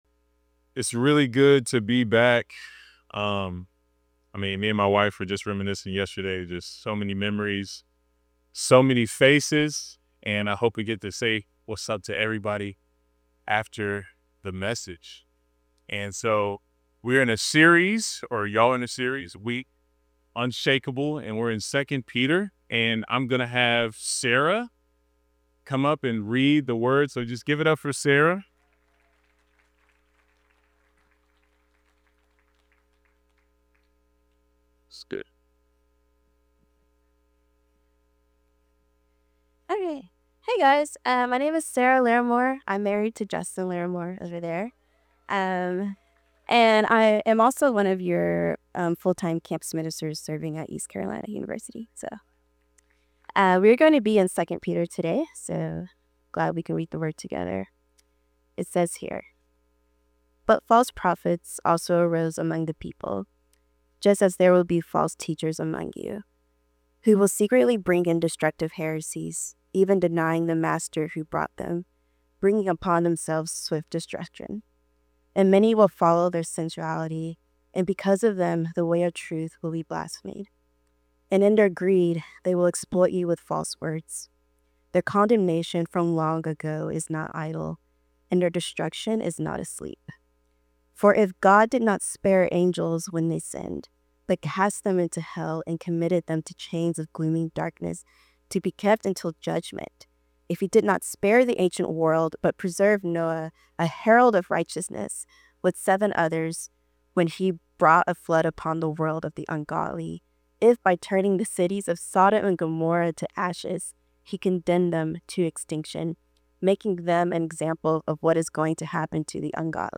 Series: Unshakable Service Type: Sunday 10am